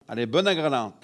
Localisation Saint-Jean-de-Monts
Catégorie Locution